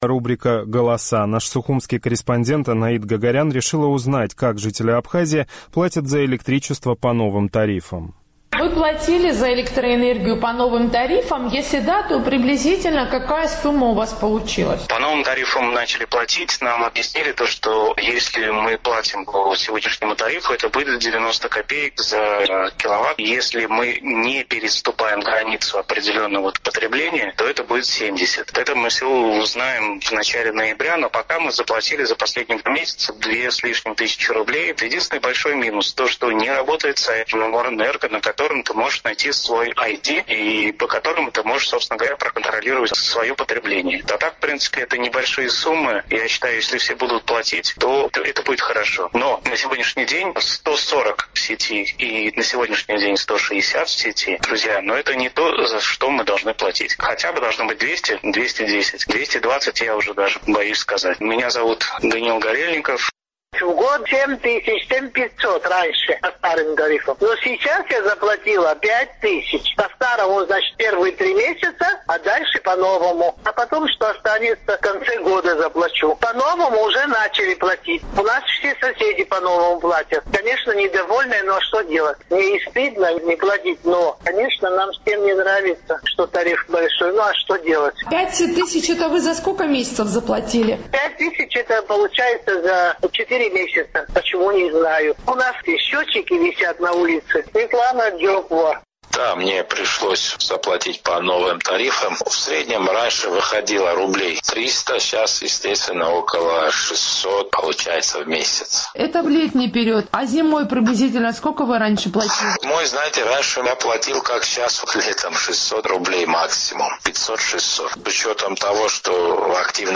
Сухумский опрос – о плате за электроэнергию после повышения тарифов
С 1 июля в Абхазии почти в два раза повысились тарифы на электроэнергию. «Эхо Кавказа» поинтересовались у жителей Абхазии, платили ли они по новым тарифам и сколько.